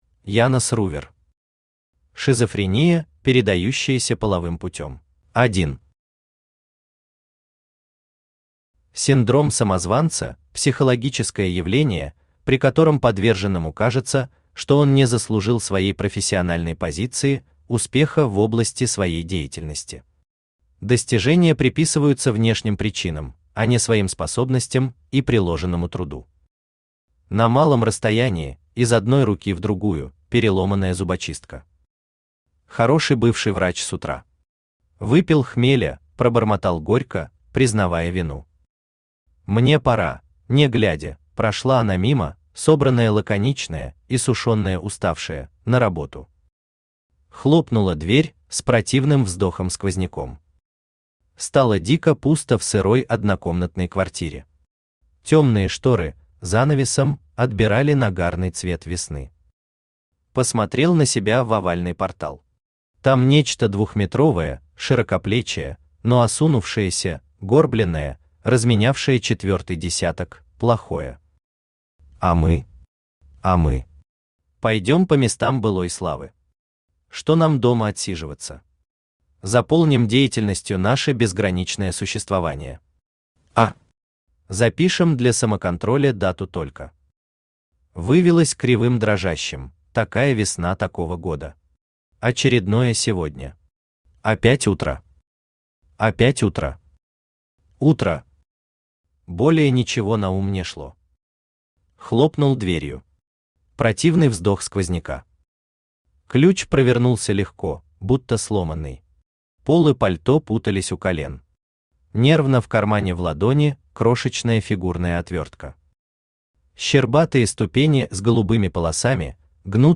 Аудиокнига Шизофрения, передающаяся половым путём | Библиотека аудиокниг
Aудиокнига Шизофрения, передающаяся половым путём Автор Янос Ли Рувер Читает аудиокнигу Авточтец ЛитРес.